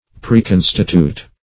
Preconstitute \Pre*con"sti*tute\, v. t. To constitute or establish beforehand.